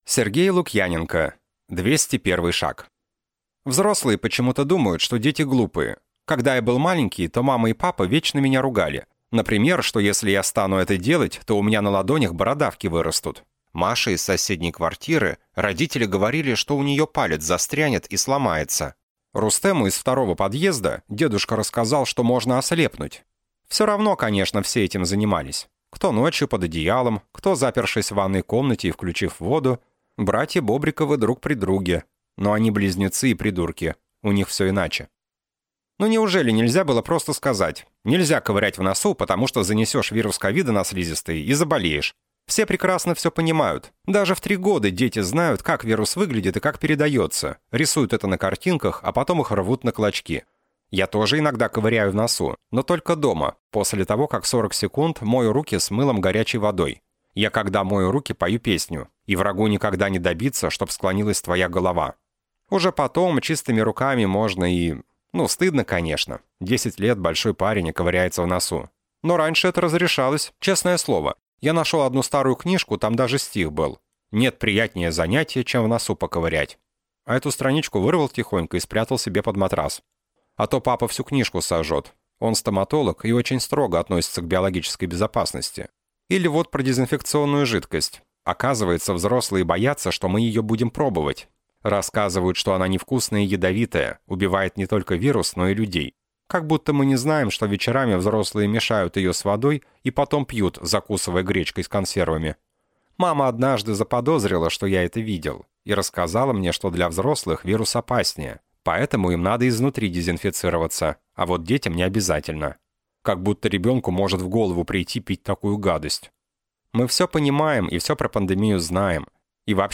Аудиокнига Двести первый шаг | Библиотека аудиокниг